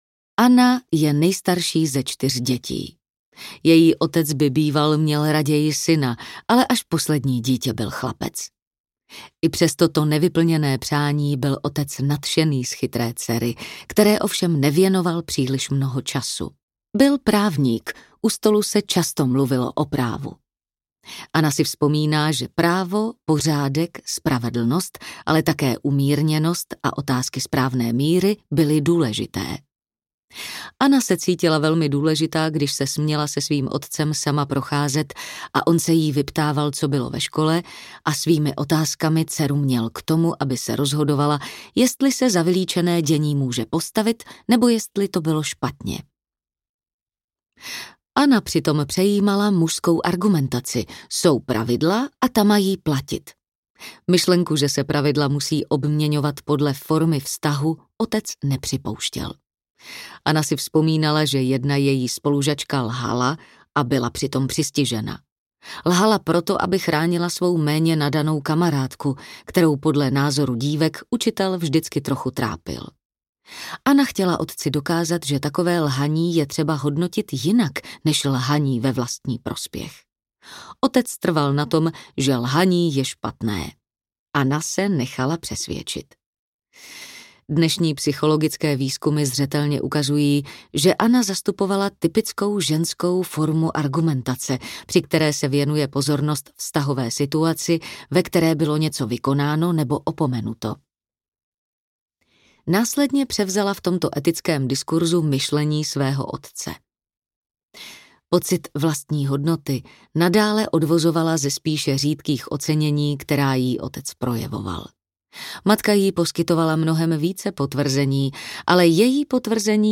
Otcové – dcery, matky – synové audiokniha
Ukázka z knihy
otcove-dcery-matky-synove-audiokniha